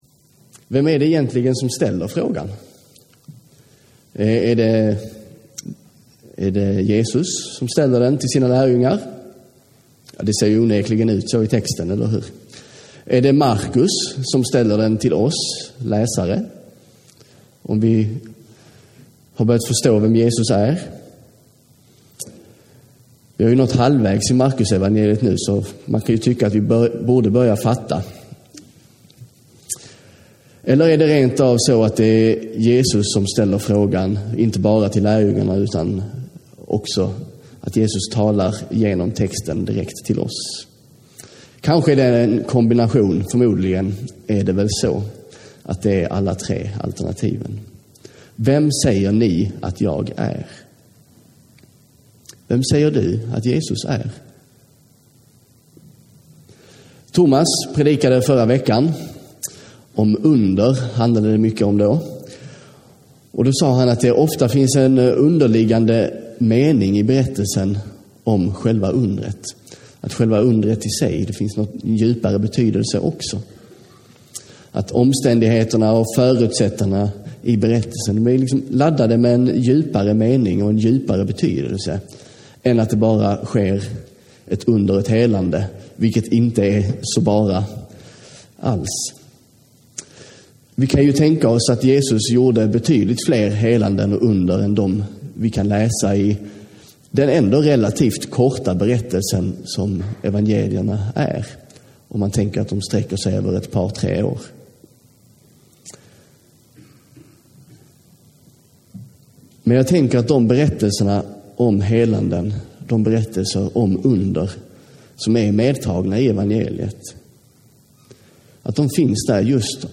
Predikan
från gudstjänsten i Slottshagskyrkan 19 november 2017.